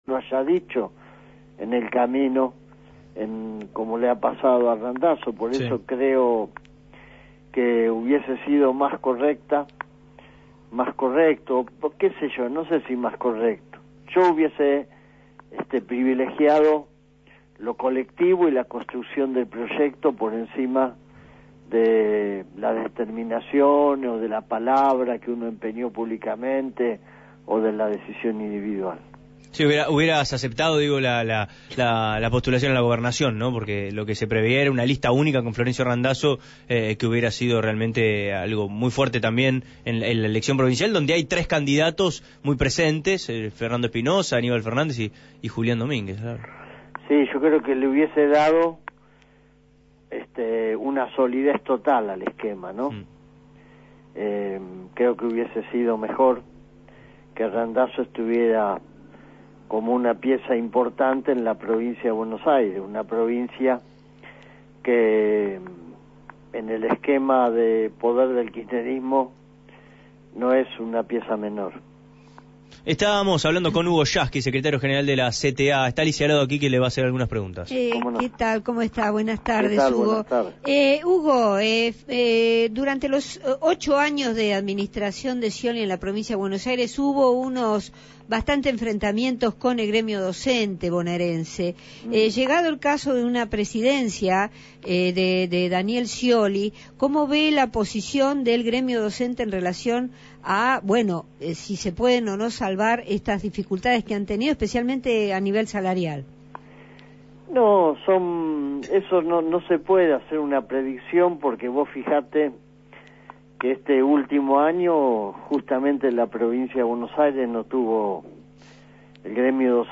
Hugo Yasky, titular de la CTA, fue entrevistado en Abramos la Boca.